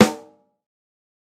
• Big Room Steel Snare Drum Sample A Key 74.wav
Royality free snare drum sound tuned to the A note.
big-room-steel-snare-drum-sample-a-key-74-W2X.wav